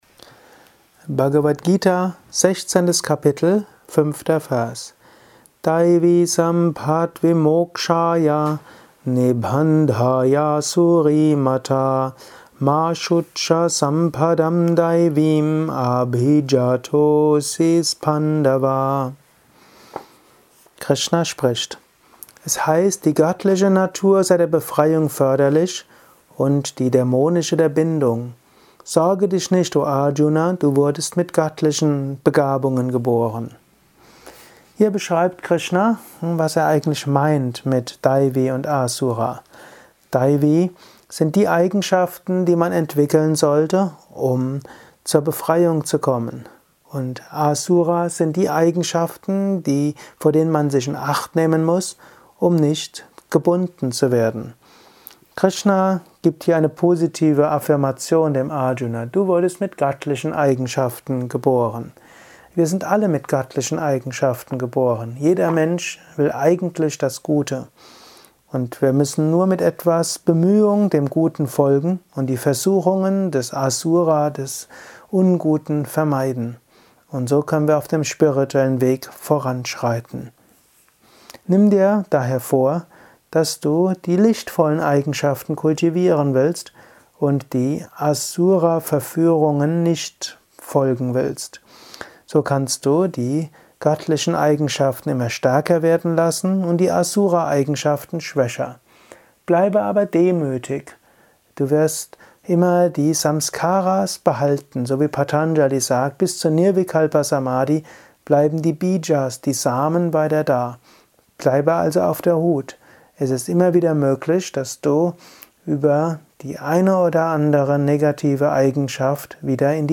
Dies ist ein kurzer Kommentar als